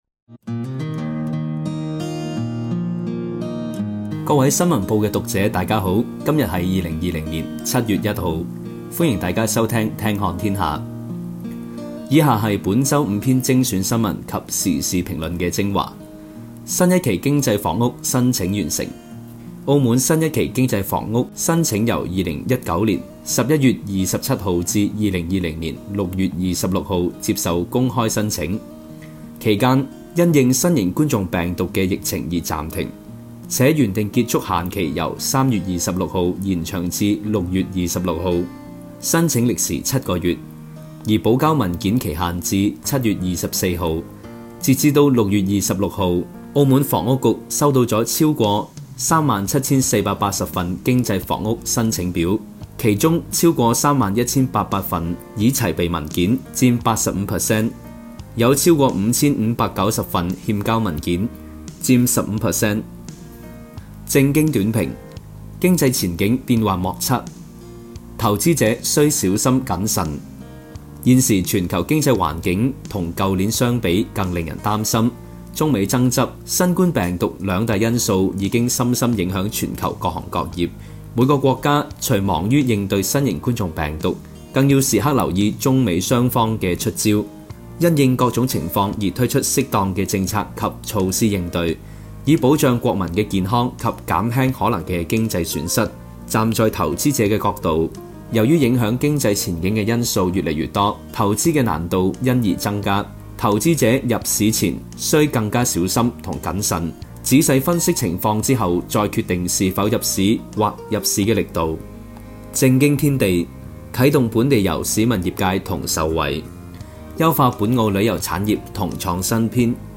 [語音播報]新聞及時事評論精華（粵語）